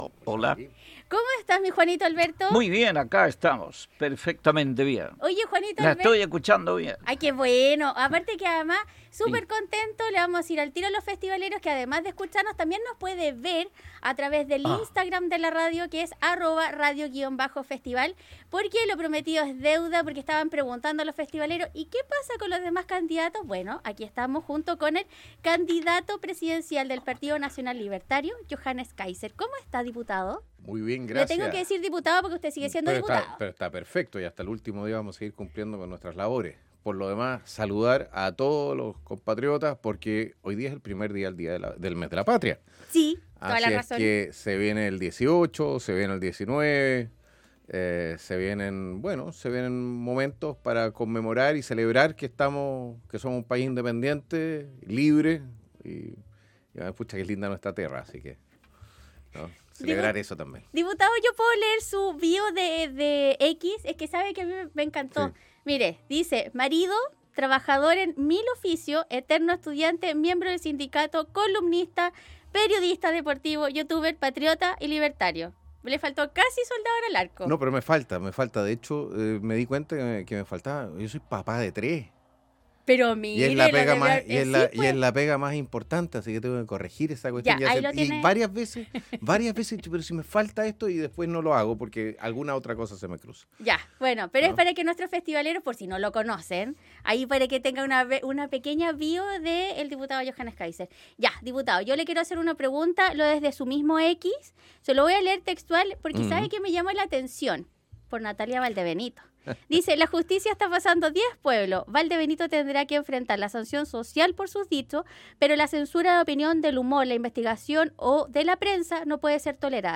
El Candidato del Partido Nacional Libertario, el Diputado Johannes Kaiser contó detalles sobre su plan de Gobierno, el proyecto de ley para identificar los restos de los detenidos desaparecidos que se encuentran en el SML, entre otros temas